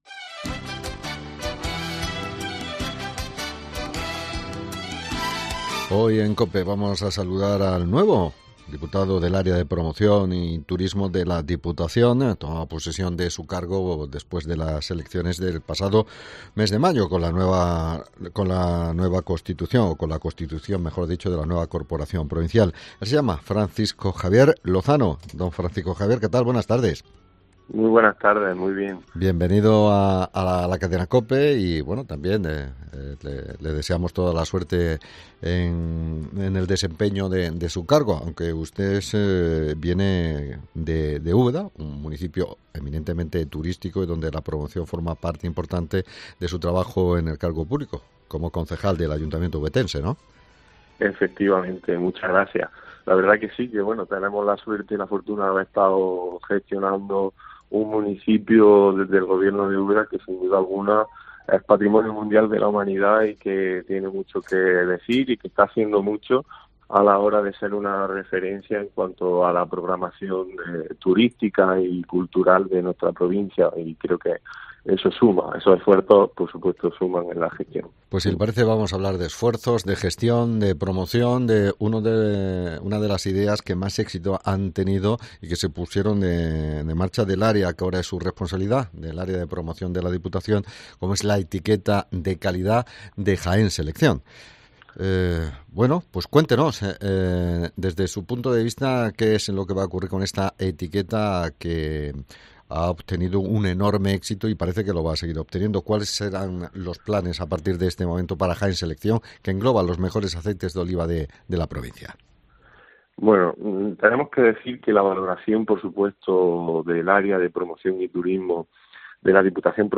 Entrevista con Francisco Javier Lozano, diputado del área de Promoción y Turismo